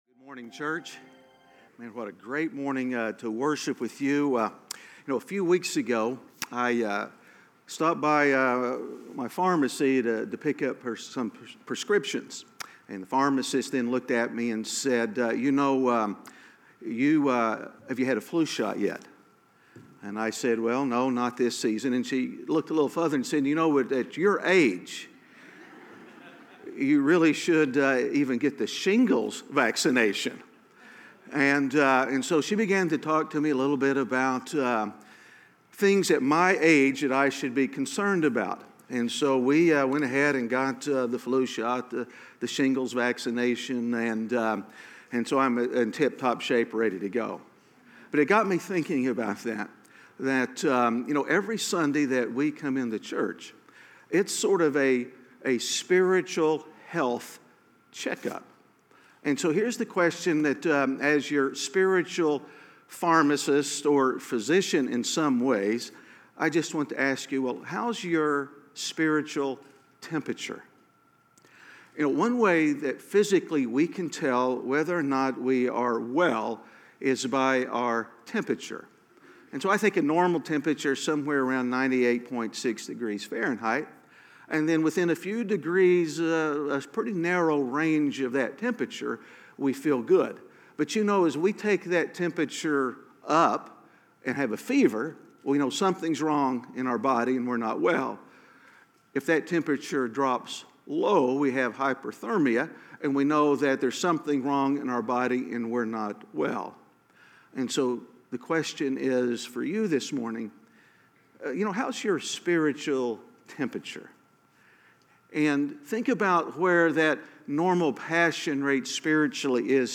A message from the series "The God Who Speaks."